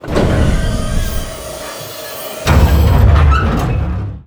bayCLOSE.wav